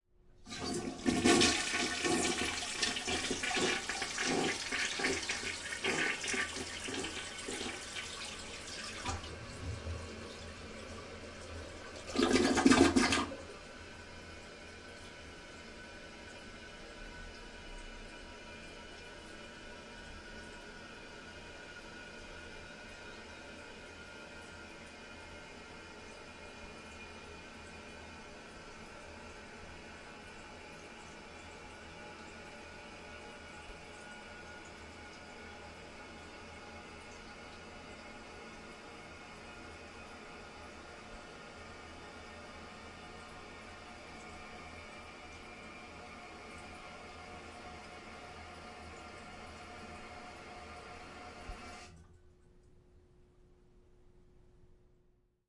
卫生间的声音 " 小便池冲水声
描述：公共浴室小便池冲洗。用Zoom H2n记录。
Tag: 公共 管道 冲洗 冲洗 失速 厕所 小便器 浴室 厕所 厕所